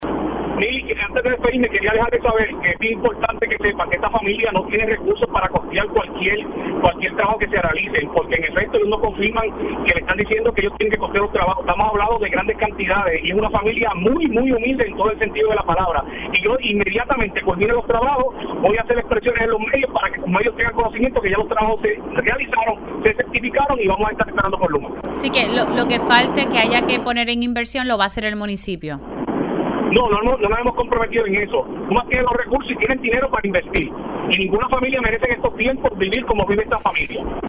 513-JOSEAN-GONZALEZ-ALC-PEnUELAS-PIDE-A-LUMA-ENERGY-QUE-COSTEE-TRABAJOS-DE-CONEXION-DE-PAREJA-SIN-LUZ-POR-48-AnOS.mp3